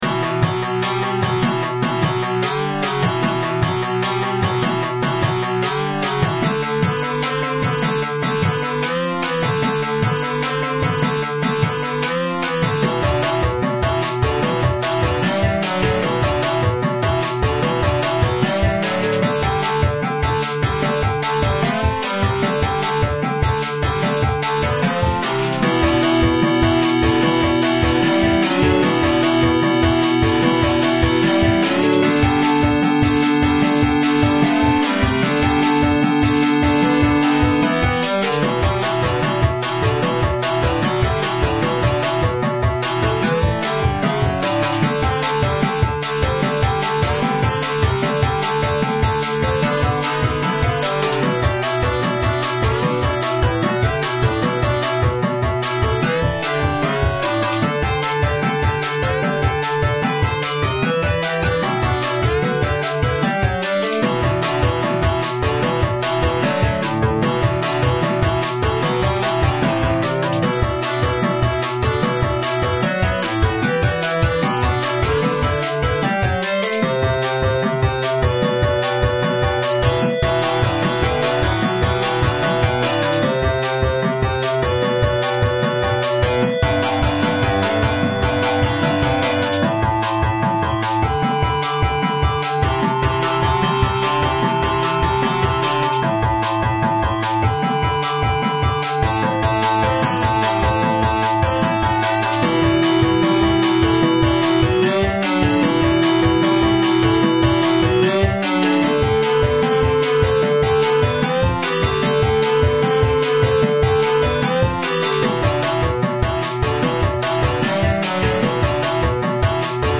Not exactly fitting for a text adventure.
contribs)Hopefully removed that weird compression glitch